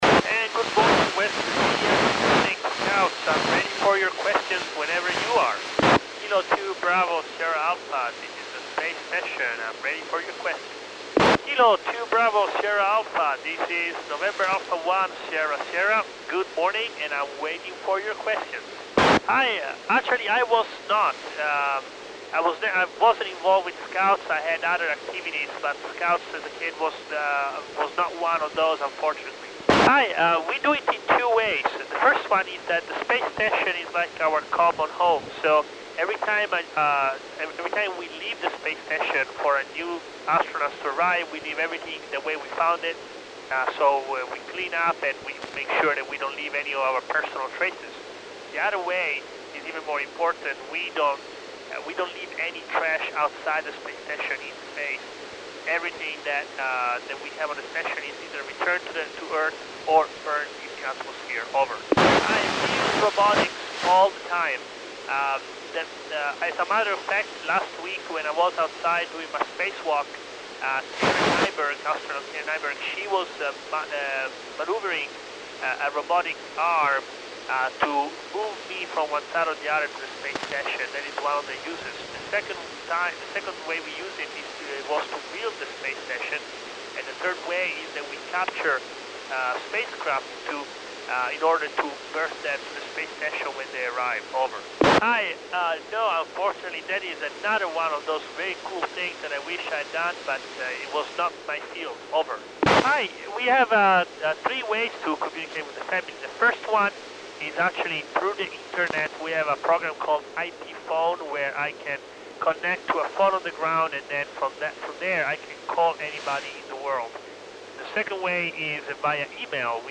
Luca Parmitano (NA1SS) speaks to scouts at the 2013 Boy Scouts of America National Jamboree at Mount Hope, WV, USA, at 1535 UTC on 20 July 2013 via K2BSA.